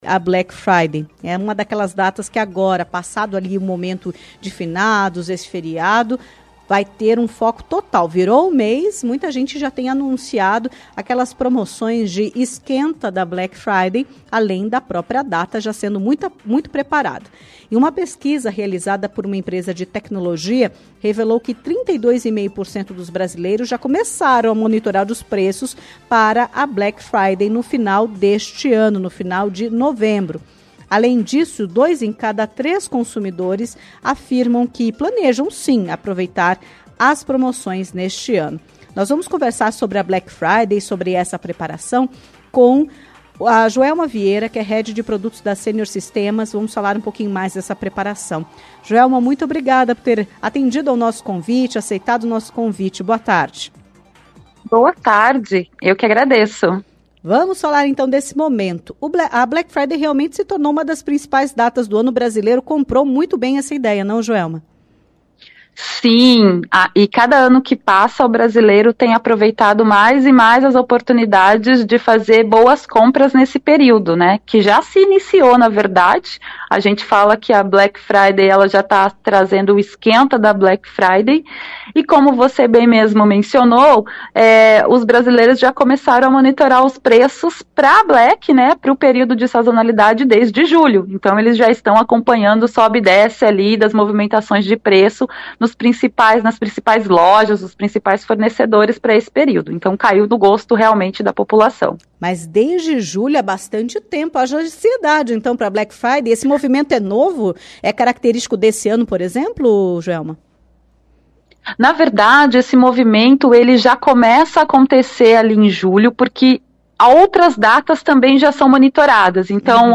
Entrevista-Black-Friday-4-11.mp3